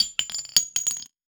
weapon_ammo_drop_22.wav